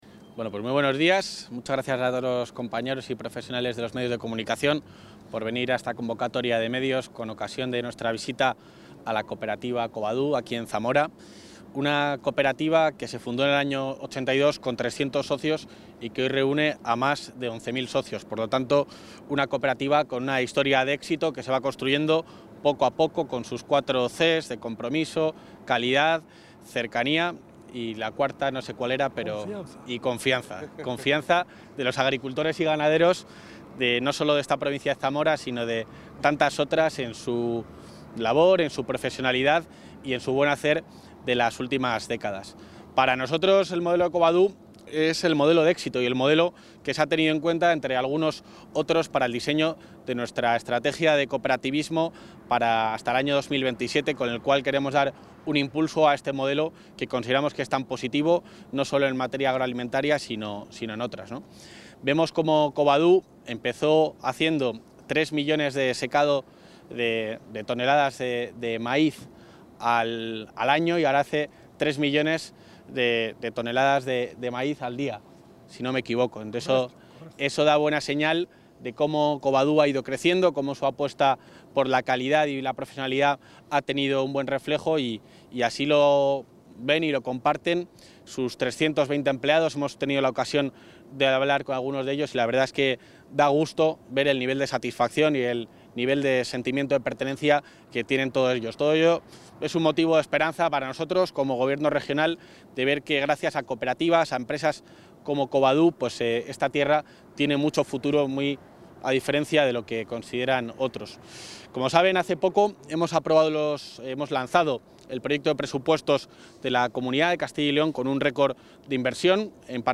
Intervención del vicepresidente de la Junta.